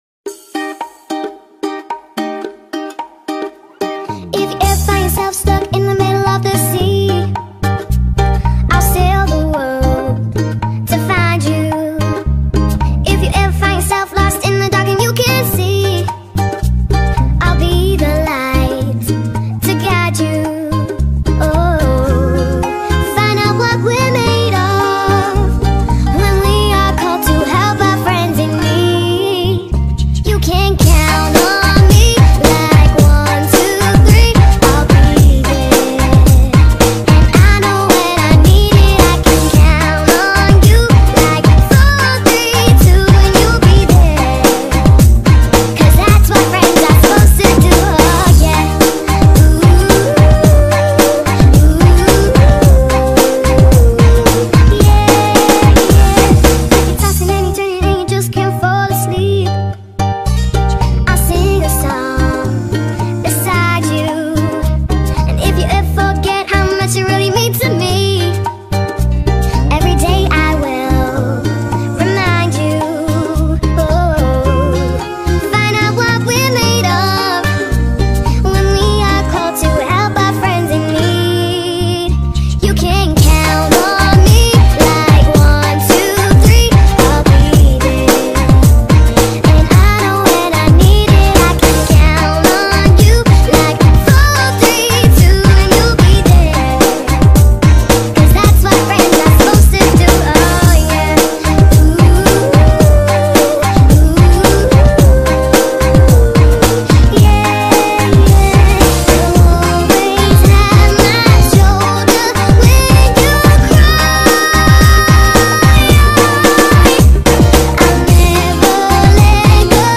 lagu dj remix